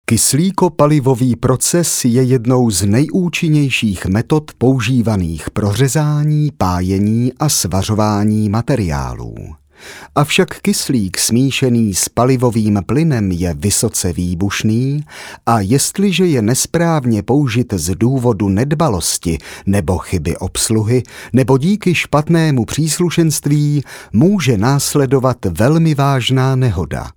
Sprecher tschechisch fĂŒr TV / Rundfunk / Industrie.
Kein Dialekt
Sprechprobe: Werbung (Muttersprache):
Professionell voice over artist from Czech.